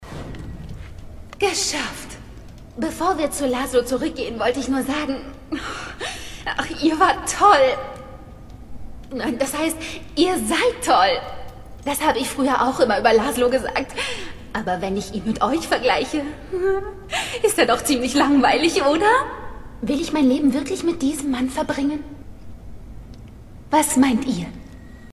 Sprecherin Deutsch & Türkisch akzentfrei. Vielseitig, wandelbar, facettenreich.
Kein Dialekt
Sprechprobe: Sonstiges (Muttersprache):
Voice Over Artist German & Turkish